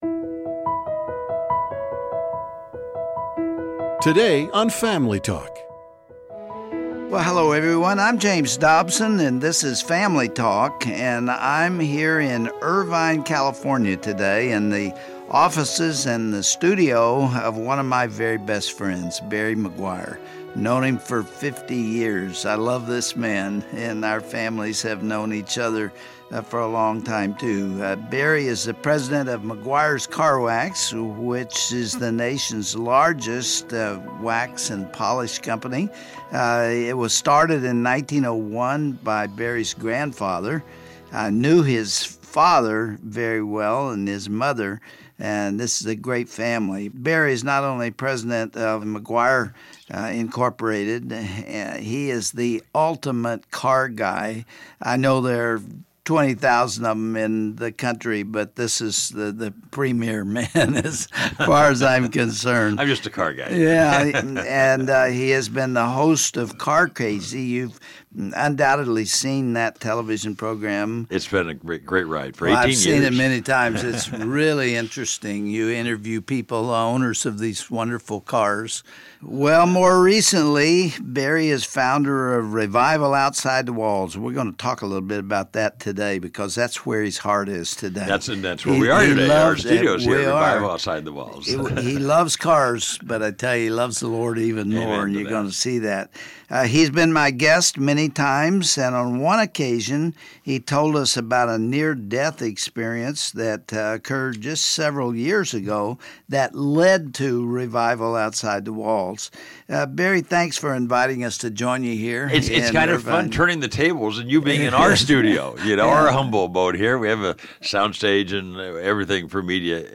Listen to this broadcast to learn practical ways believers can confidently live out their faith.